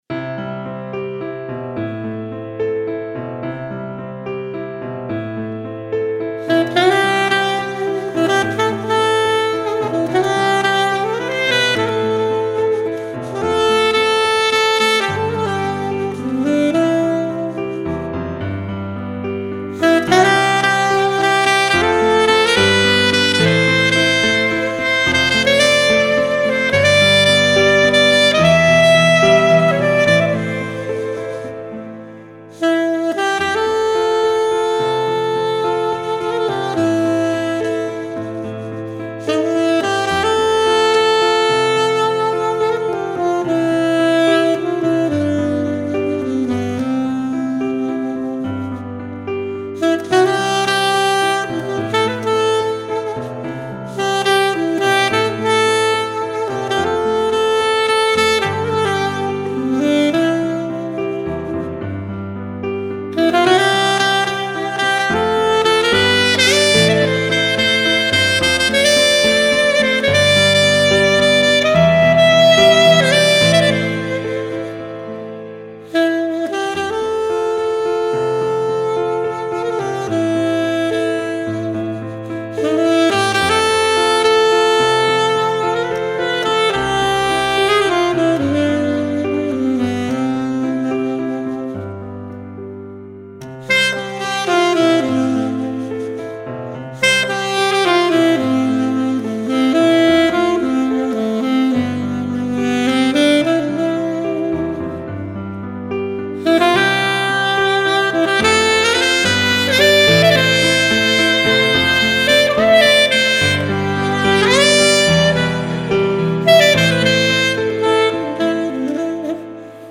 SAXOPHONIST FÜR EMPFÄNGE, TRAUUNGEN, EVENTS & PARTYS
mit Playback